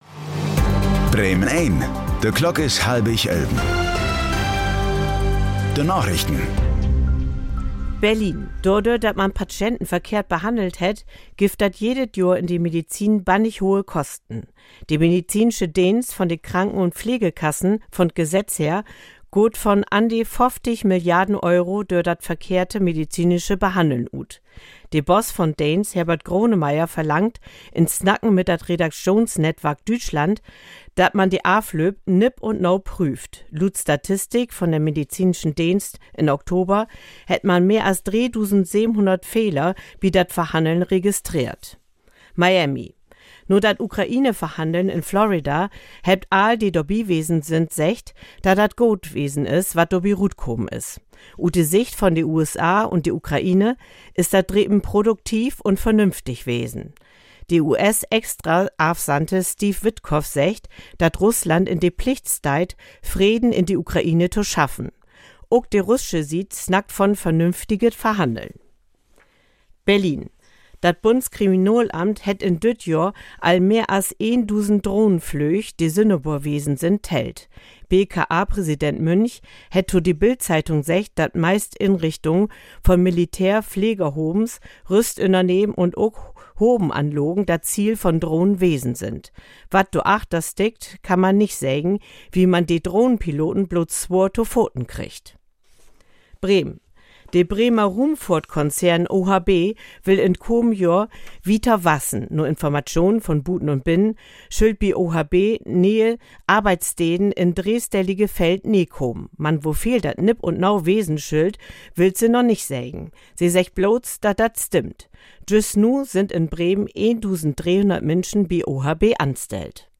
Plattdüütsche Narichten vun'n 22. Dezember 2025